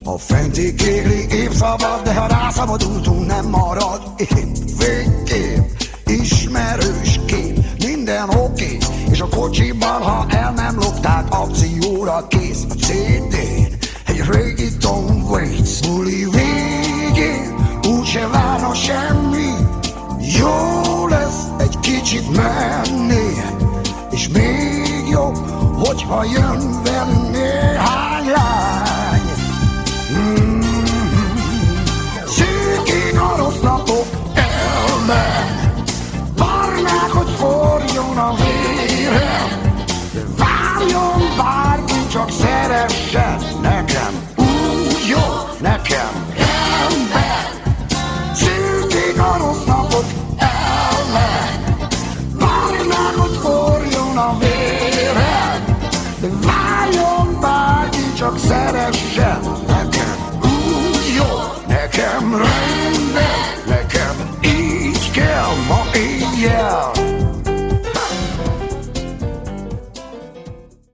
Lattmann Béla: Bass